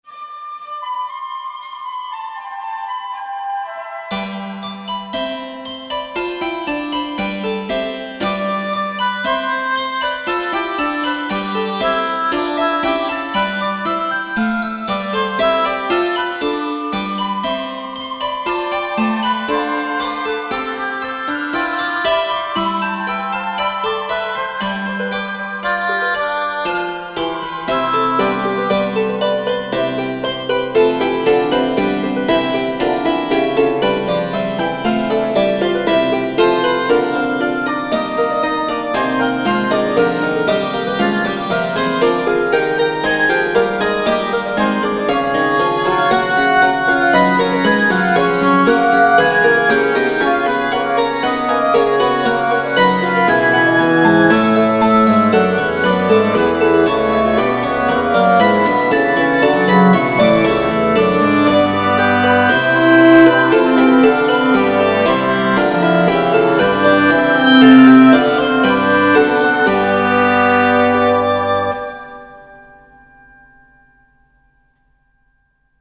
Bei beiden handelt es sich um Kanonabwandlungen für Lord Milony’s Dancebook, entsprechend sind sie auch mit Virginal, keltischer Harfe, Gamben und Holzbläsern (z. B. Flöten) orchestriert.
Kanon ‚Sommerliebe‘